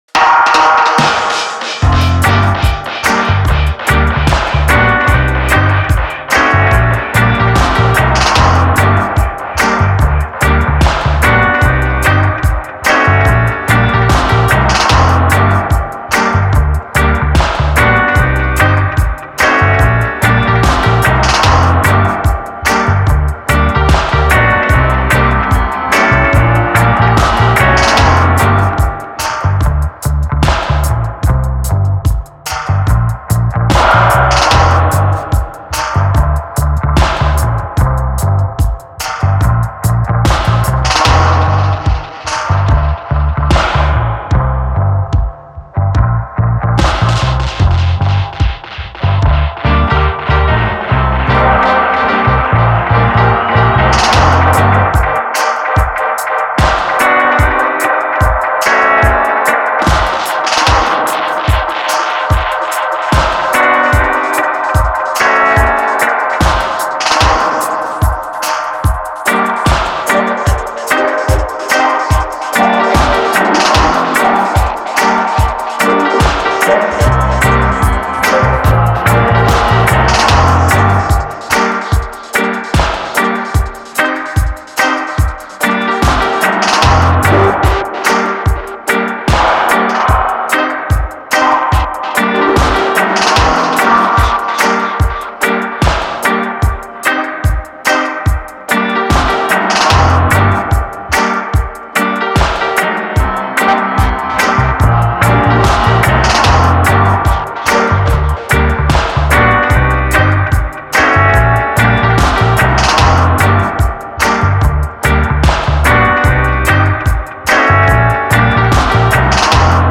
Smooth downbeat dub with cool piano notes.